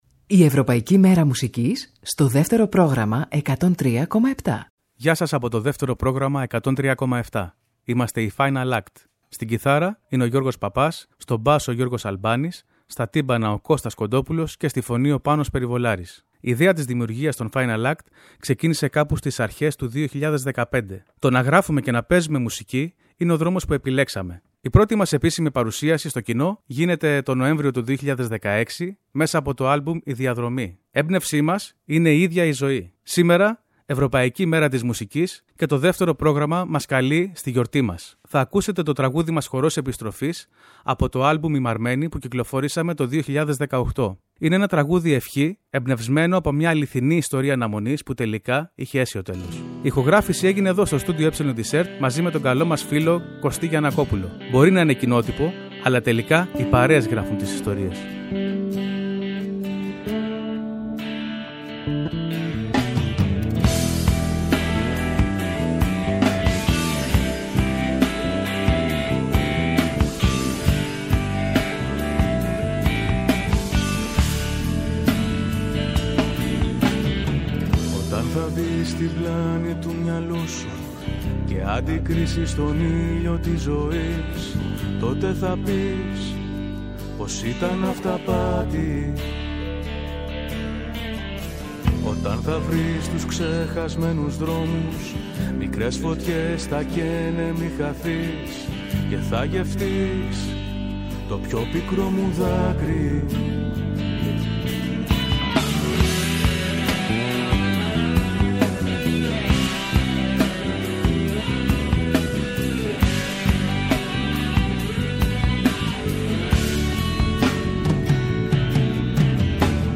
Οι Final Act αυτοσυστήνονται και θα μας προτείνουν ένα τραγούδι τους. Mοιράζονται μαζί μας πληροφορίες για τη μέχρι σήμερα πορεία τους αλλά και την πηγή της έμπνευσής τους.